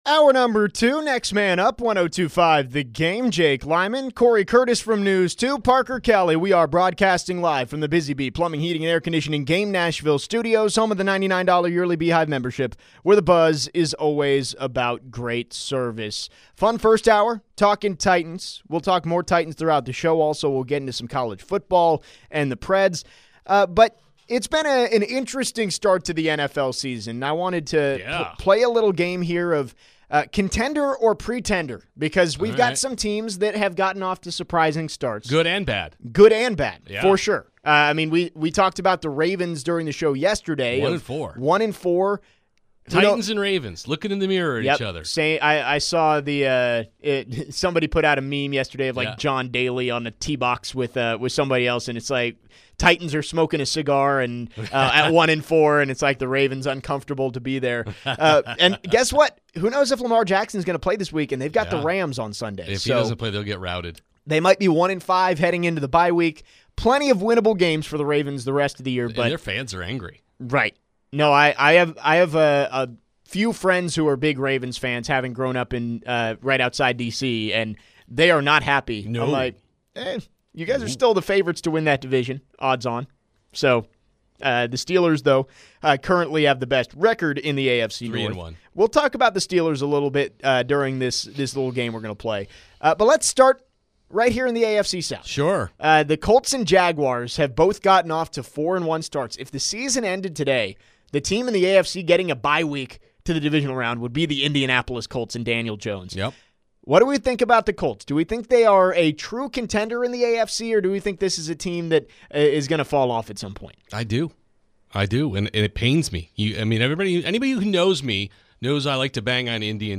The guys identify who the contenders and pretenders are in the NFL. Are the Colts and Jags legit?